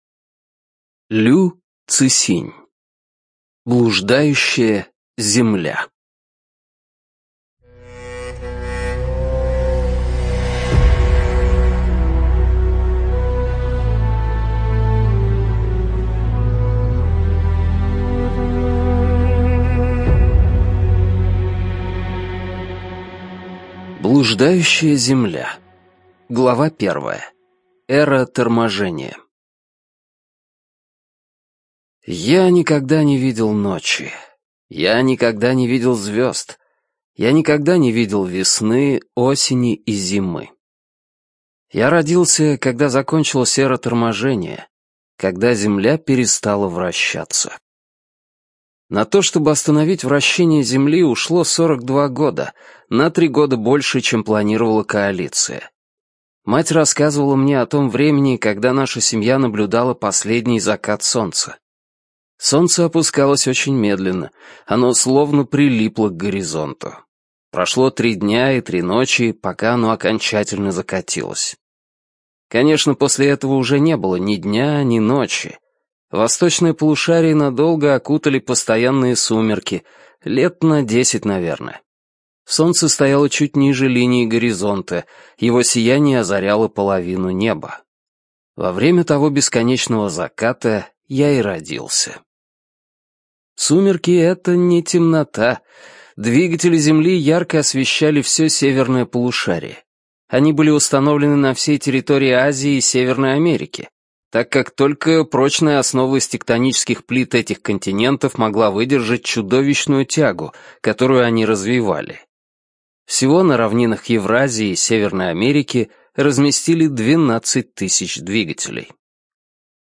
ЖанрФантастика
читать невозможно, какафония музыкальная невыносима.